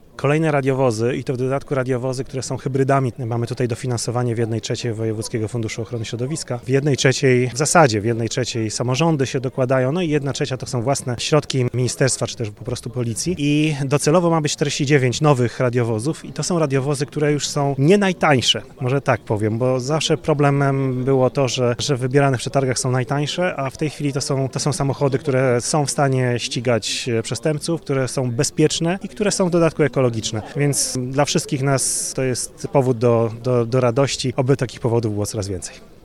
Wszystkie nowe radiowozy zostały wyposażone w napęd hybrydowy. O inwestycji mówi Maciej Awiżeń, wojewoda dolnośląski.